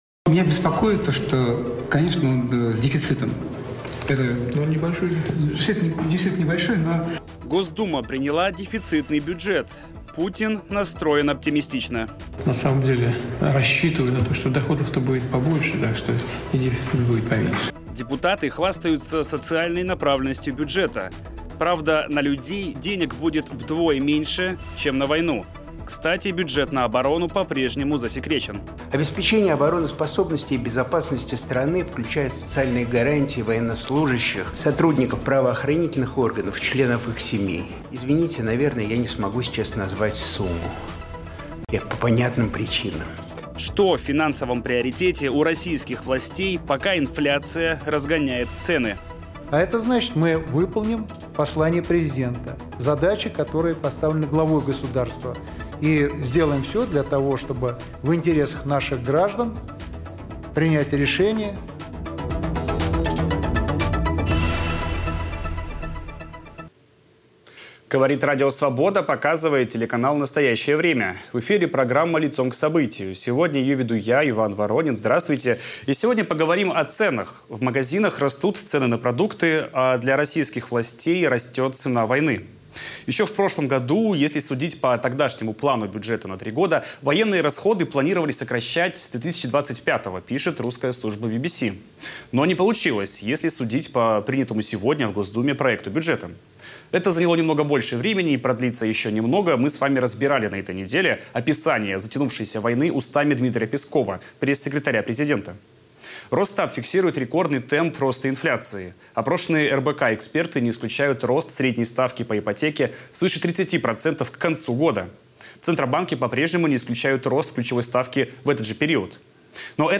В ежедневном режиме анализируем главные события дня. Все детали в прямом эфире, всегда Лицом к Событию